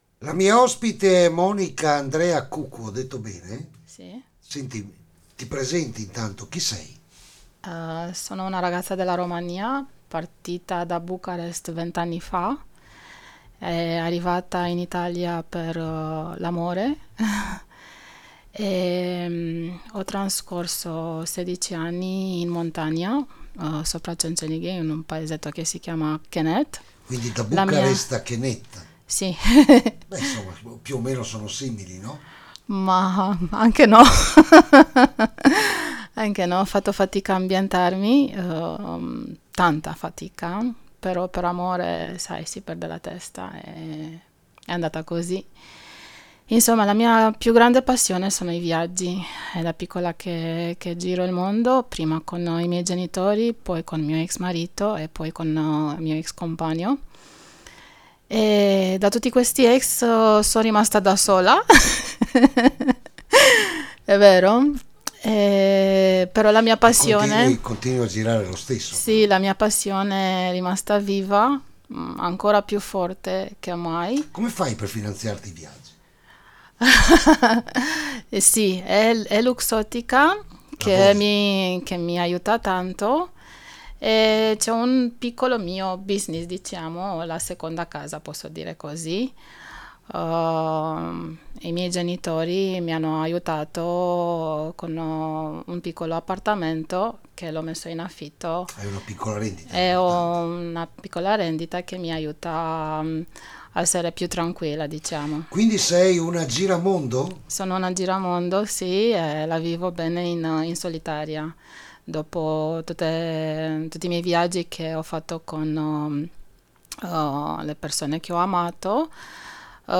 l’intervista alla radio